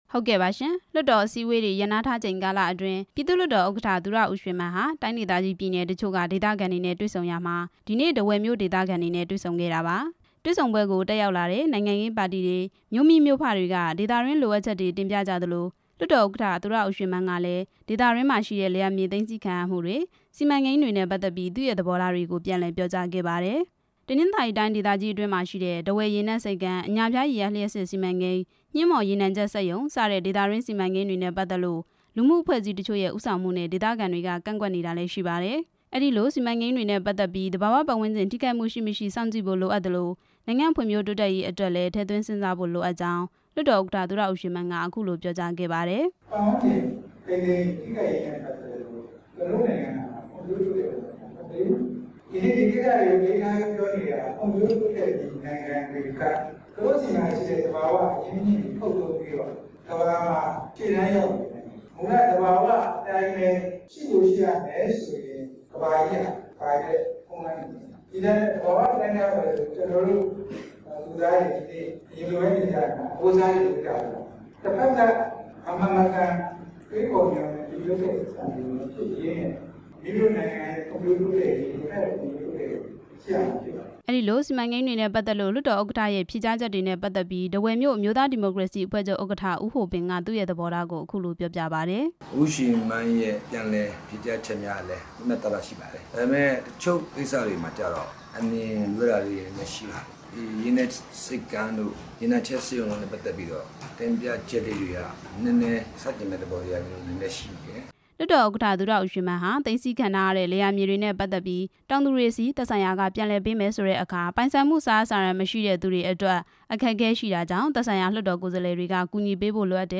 ဒီနေ့ ထားဝယ်မြို့တော်ခန်းမမှာ ကျင်းပတဲ့ ဒေသခံ လွှတ်တော်အမတ်တွေ၊ မြို့မိမြို့ဖတွေ၊ နိုင်ငံရေး ပါတီတွေက ခေါင်းဆောင်တွေ၊ တိုင်းရင်းသား လက်နက်ကိုင်အဖွဲ့တွေနဲ့ တွေ့ဆုံပွဲမှာ သူရ ဦးရွှေမန်းက အခုလို ထည့်သွင်းပြောကြားခဲ့တာပါ။